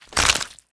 bone_breaking_53.wav